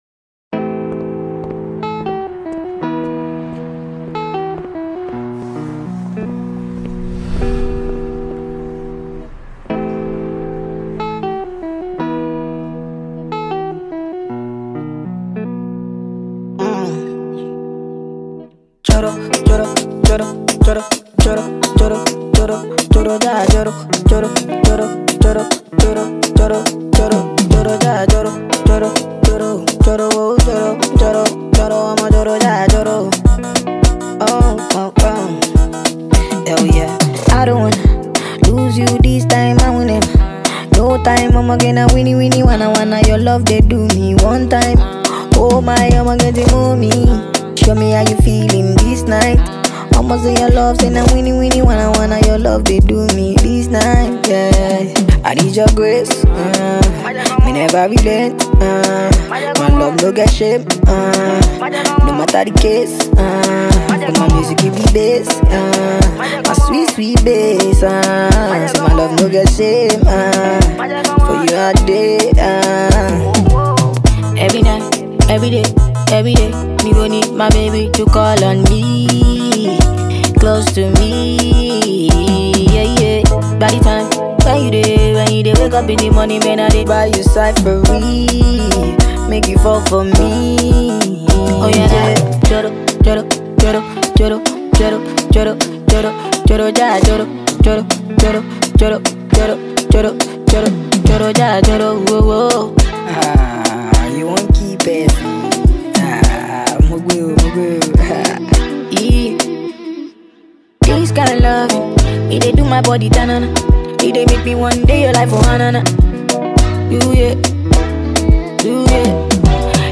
sped up remix
nightcore remix